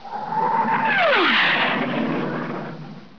AirStrike.wav